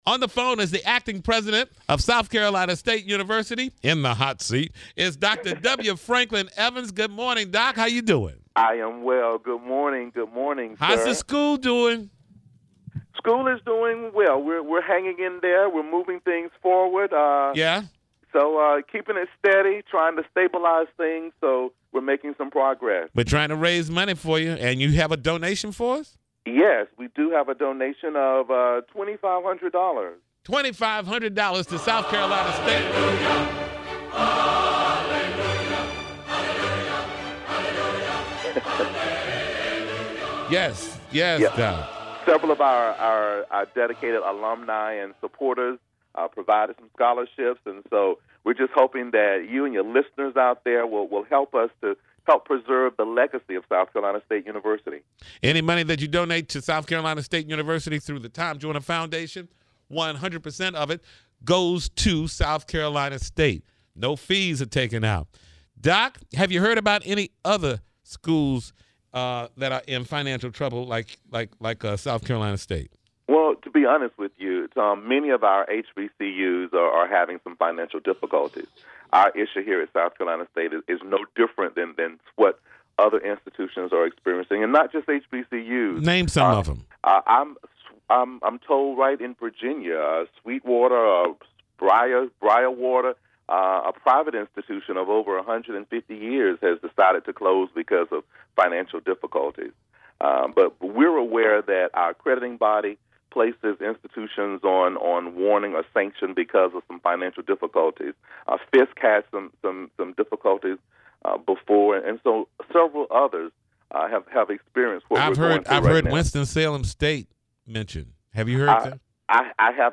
South Carolina State University President Dr. W. Franklin Evans talks to the Tom Joyner Morning Show about the state of the university’s pending accreditation and what Alumni can do to help their Alma Mater.